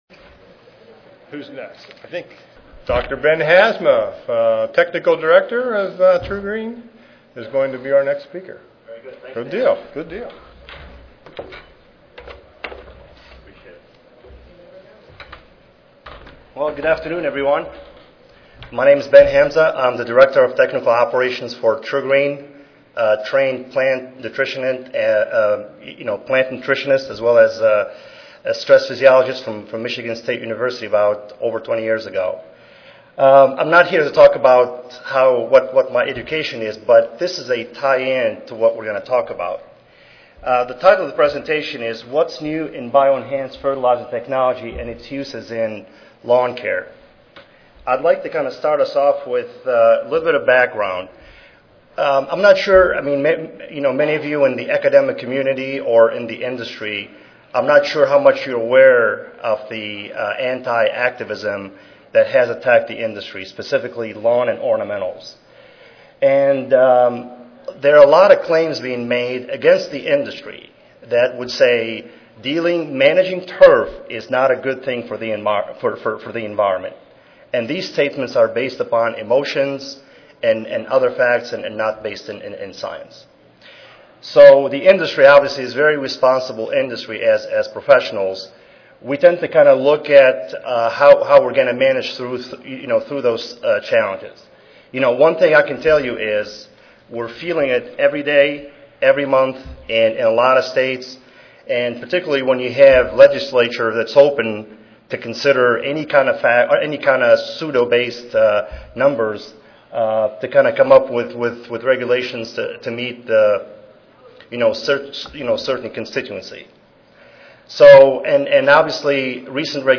Henry Gonzalez Convention Center, Room 214B
Recorded Presentation